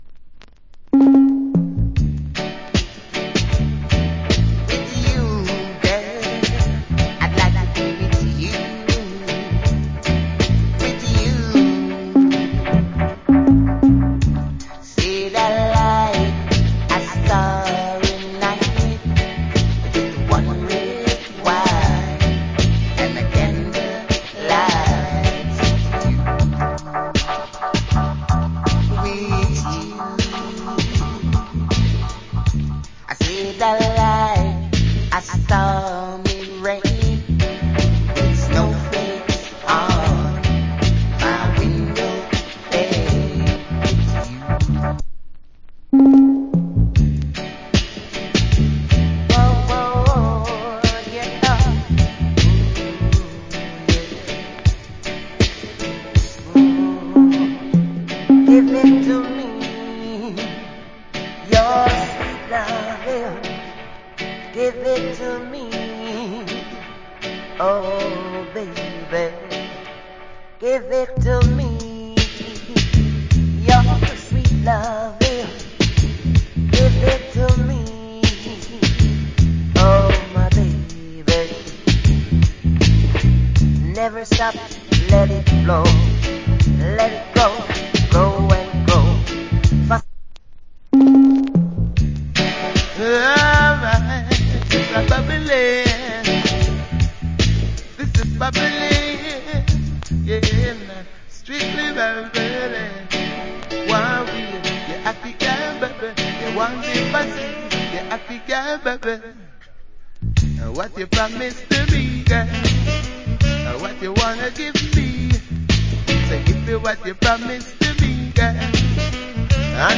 Nice Channel One Dancehall.